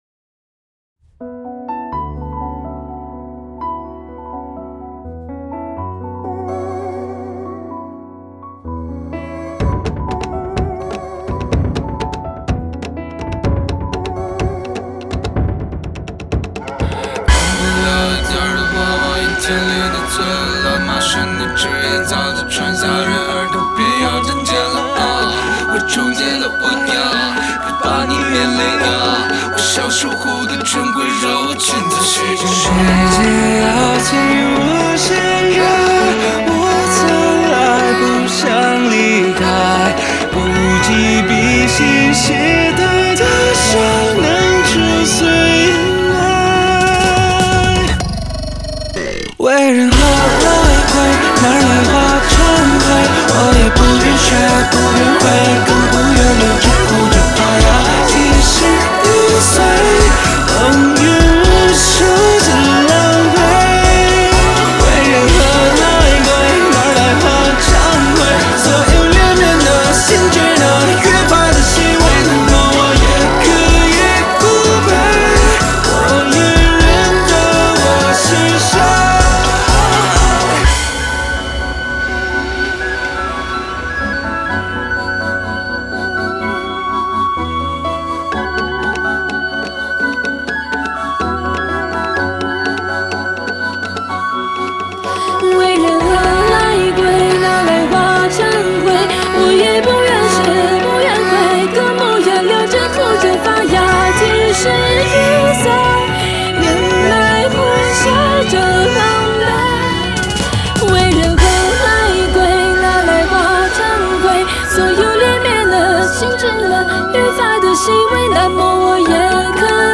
Ps：在线试听为压缩音质节选，体验无损音质请下载完整版 洋不了一点点的娃娃，阴天里的最会乱麻。